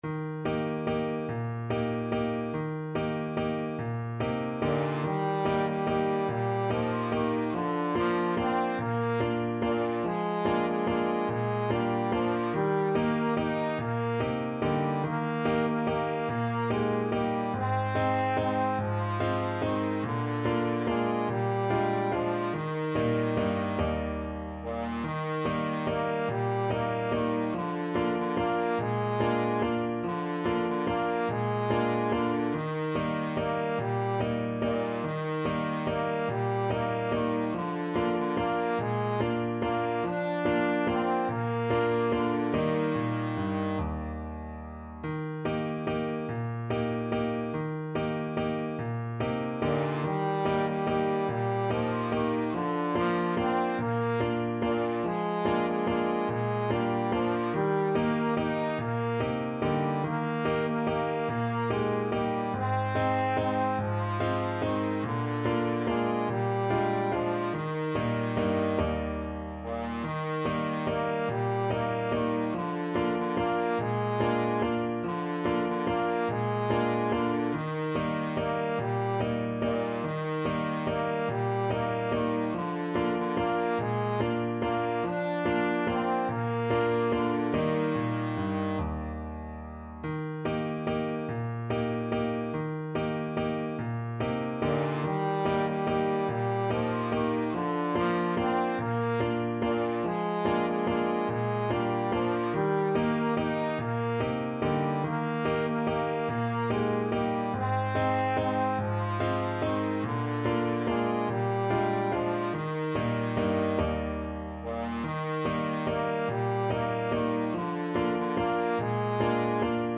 Steady one in a bar .=c.48
3/8 (View more 3/8 Music)
Bb3-Eb5
Swiss